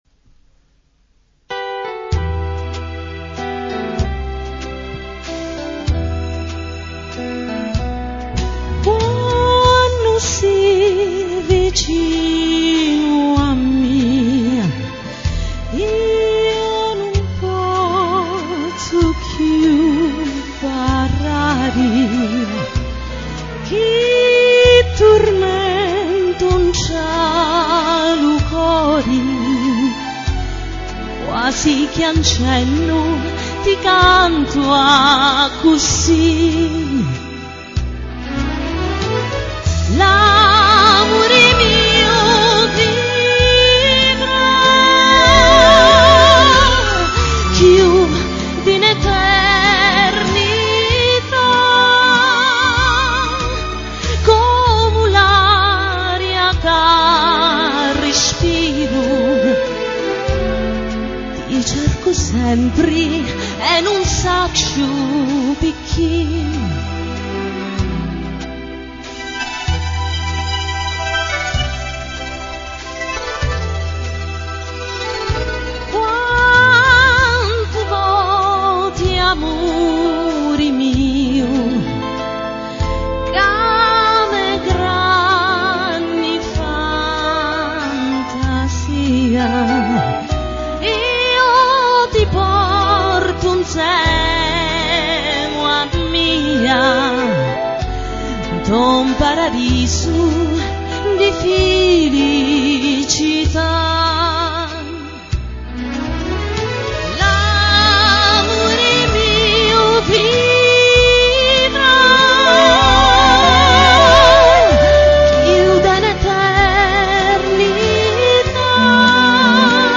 clarinetto e sax alto
voce e violino